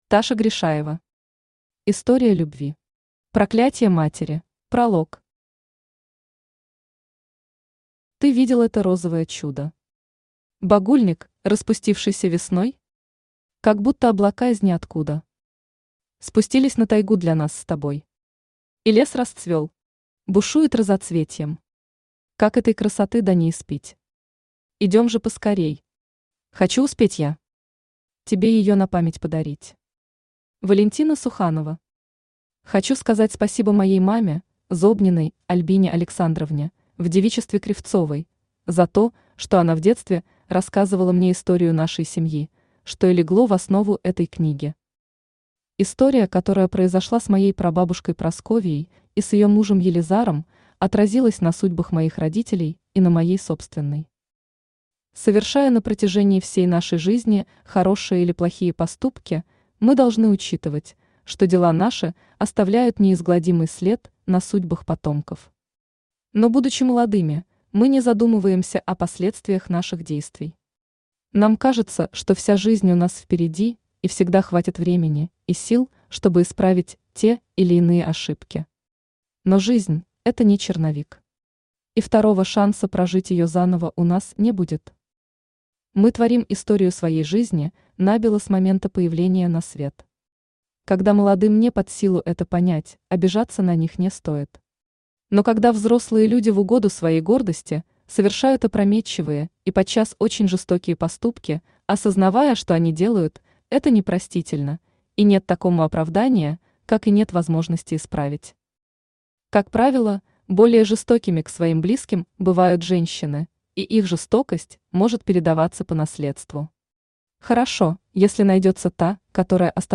Аудиокнига История любви. Проклятье матери | Библиотека аудиокниг
Проклятье матери Автор Таша Гришаева Читает аудиокнигу Авточтец ЛитРес.